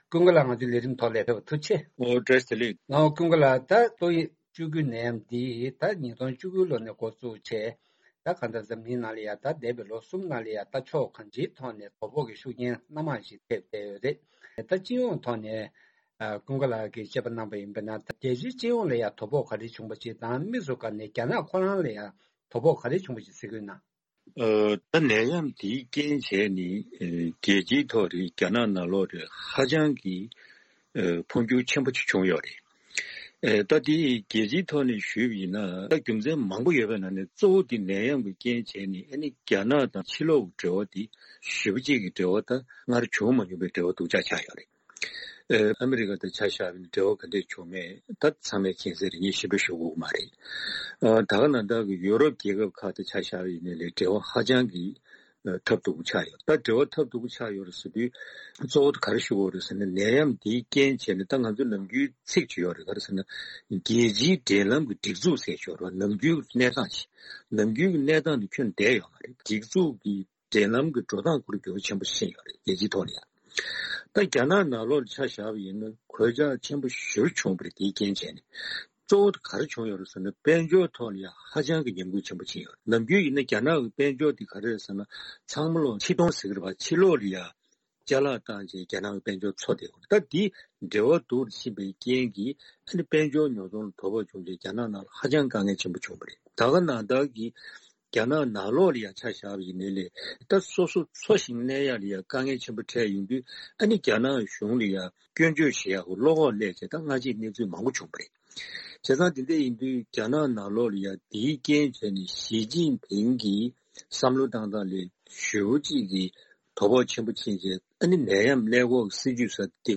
དཔྱད་གཞིའི་དགོངས་ཚུལ་བཅར་ཞུས་པ་ཞིག་གསན་གྱི་རེད།